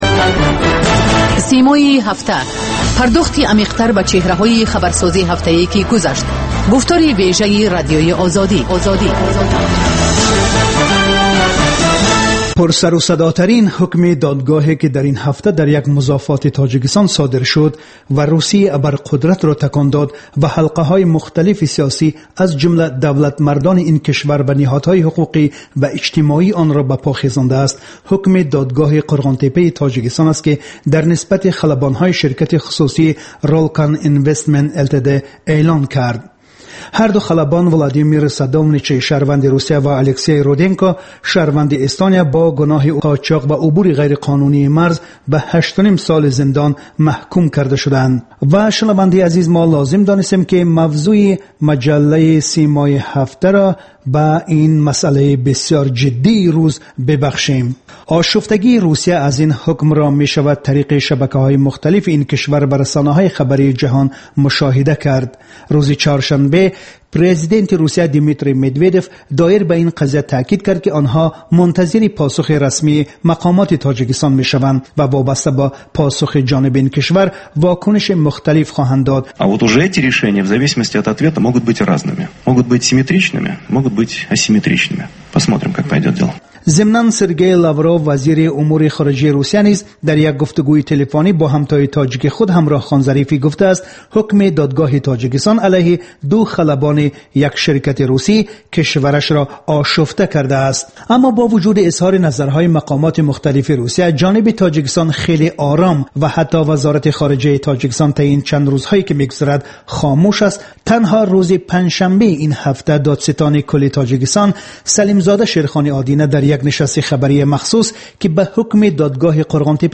Талоши чеҳранигорӣ аз афроди хабарсоз ва падидаҳои муҳими Тоҷикистон, минтақа ва ҷаҳон. Гуфтугӯ бо коршиносон.